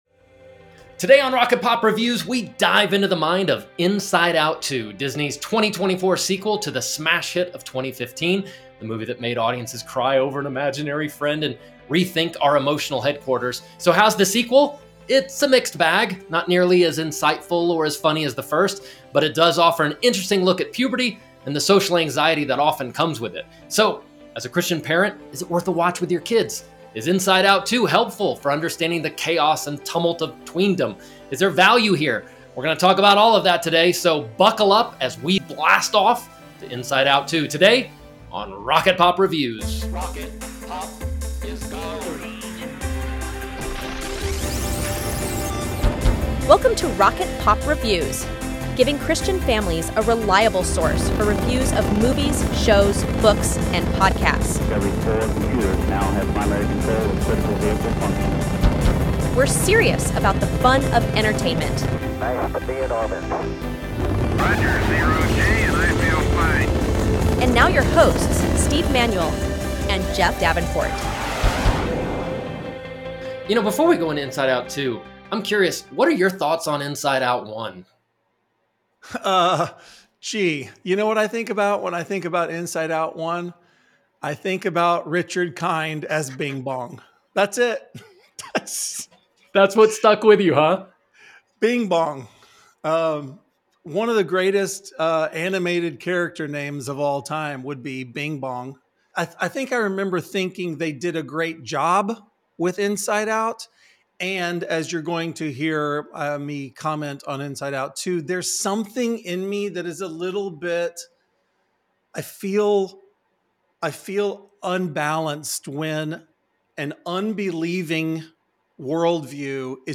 INSIDE OUT 2 | Movie Review